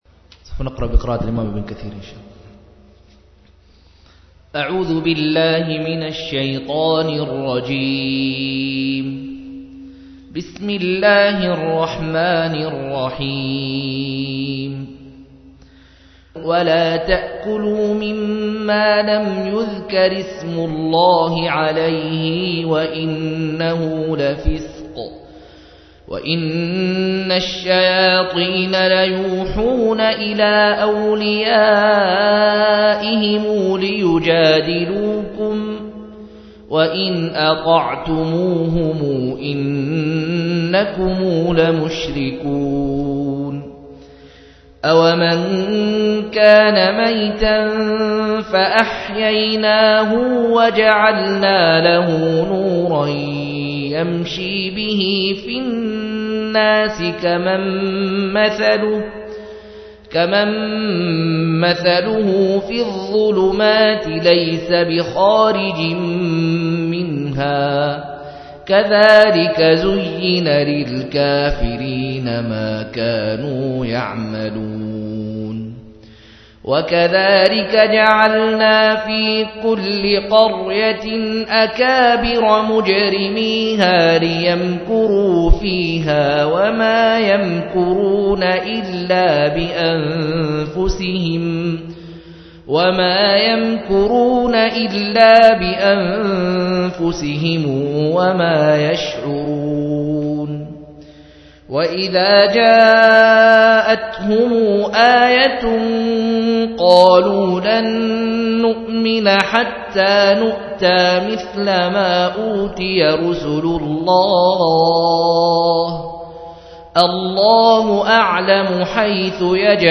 137- عمدة التفسير عن الحافظ ابن كثير رحمه الله للعلامة أحمد شاكر رحمه الله – قراءة وتعليق –